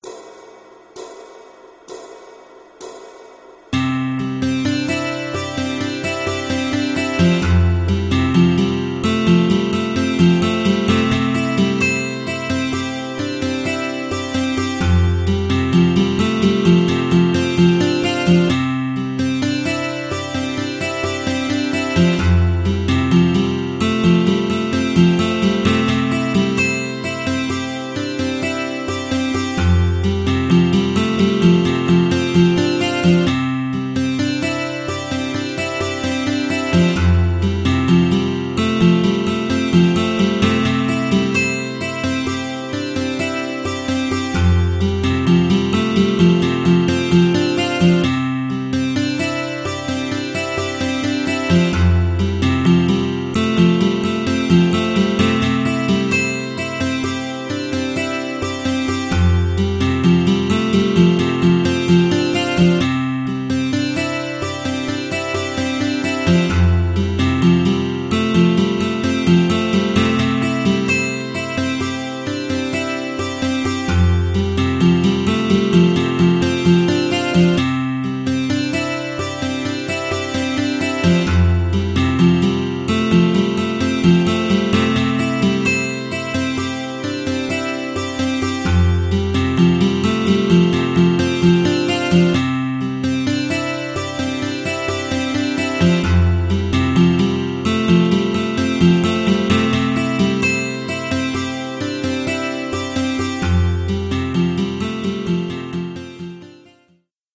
Backing Track http